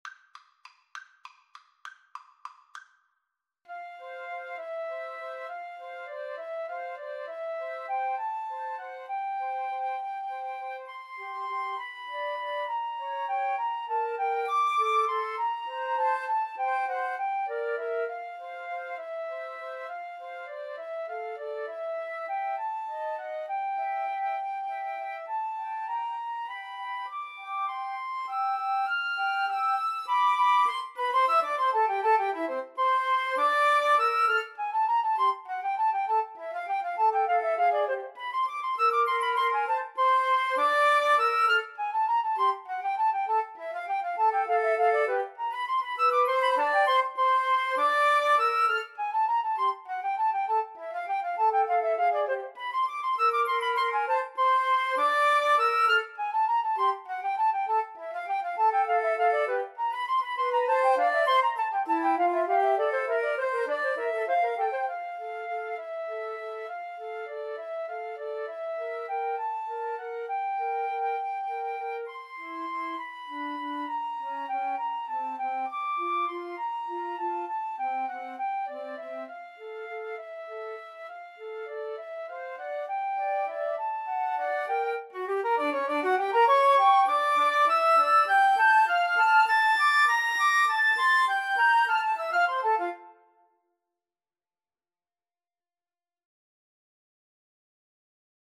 Free Sheet music for Flute Trio
F major (Sounding Pitch) (View more F major Music for Flute Trio )
Classical (View more Classical Flute Trio Music)